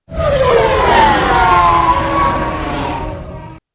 flyby.wav